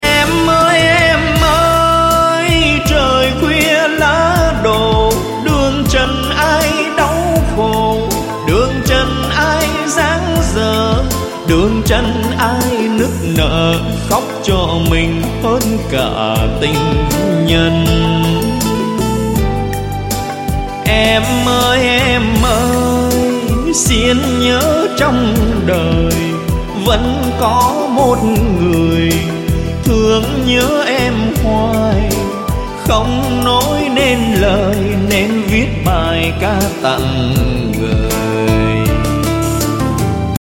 Thể loại nhạc chuông: Nhạc trữ tình